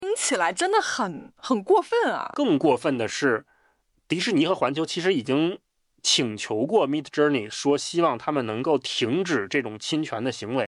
豆包交付的AI播客节目以男女双人对谈的形式进行，能对用户上传的网页、文件等进行深度改造，使其更适合听众消费。
AI播客对谈的节奏流畅自然，还会有大量的语气词、附和、停顿，这很好地模拟了真人博客中的口语习惯，有效提升了拟人程度。
实测多个案例后，智东西发现豆包生成的AI播客基本遵循了一个特定的模式——女主持人负责控制播客的节奏、提问等，男主持人负责主要内容的讲述。
具体的收听体验层面，可以听到AI主播们会通过调整语速等方式来强调重点信息。
也会使用一些语气词和包含情感的评价，这加强了播客的“真人感”。